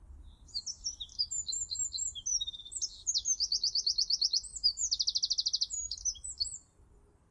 نام انگلیسی :Eurasian wren